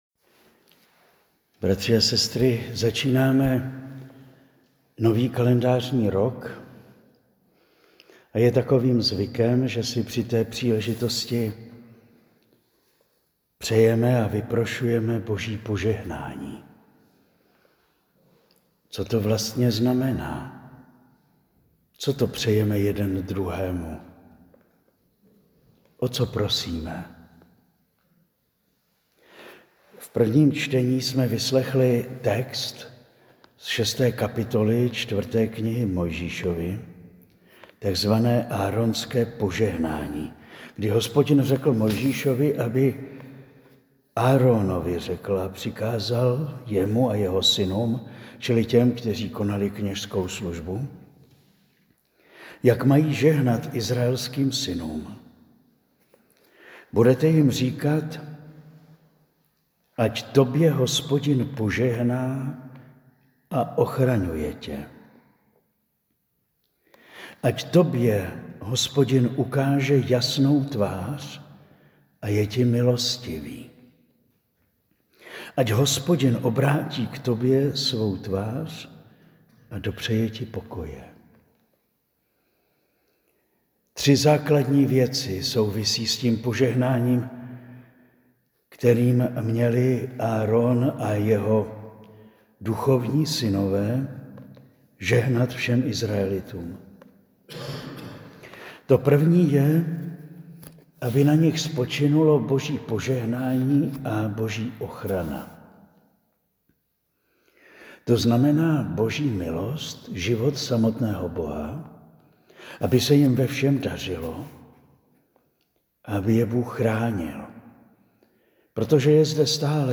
Kázání zaznělo o slavnosti Matky Boží, Panny Marie dne 1. 1. 2026 a můžete si ho stáhnout zde.